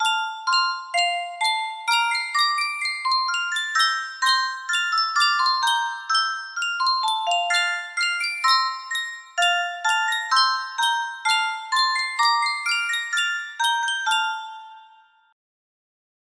Sankyo Music Box - Baa Baa Black Sheep G5 music box melody
Sankyo Music Box - Baa Baa Black Sheep G5
Full range 60